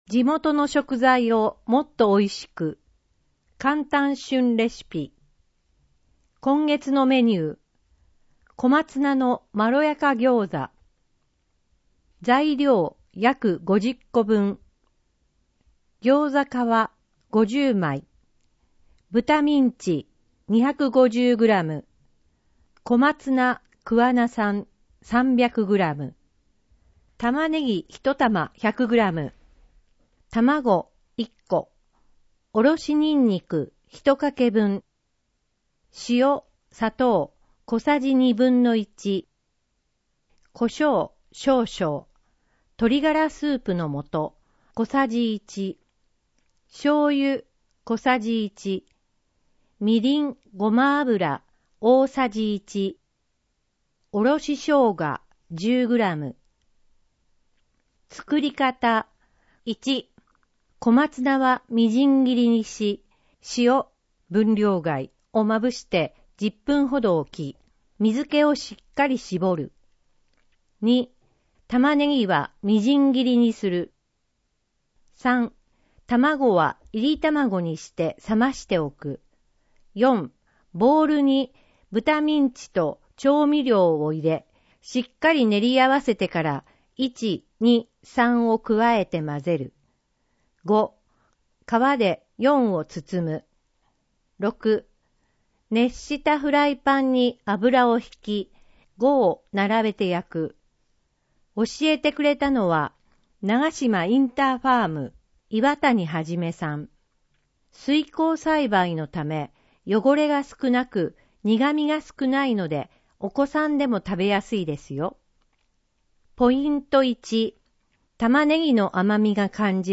なお、「声の広報くわな」は桑名市社会福祉協議会のボランティアグループ「桑名録音奉仕の会」の協力で制作しています。